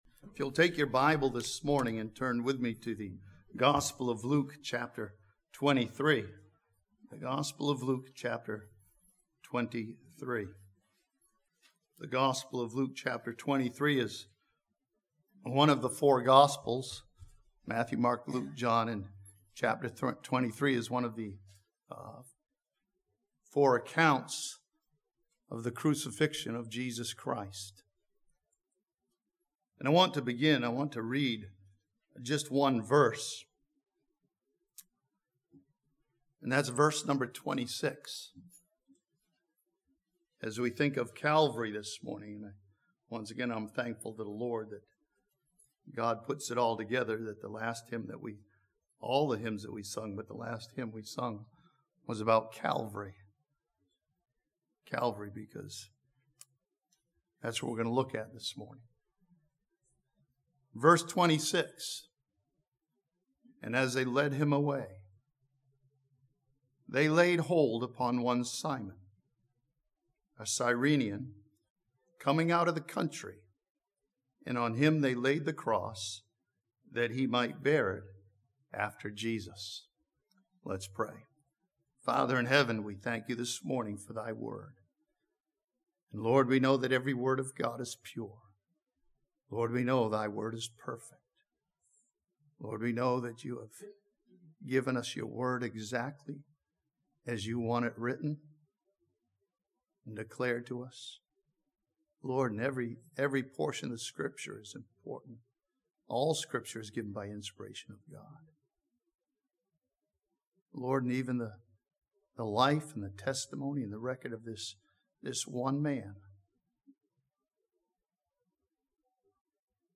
This sermon from Luke chapter 23 follows Simon the Cyrenian on his journey to Jerusalem and then as he carries the cross of Christ.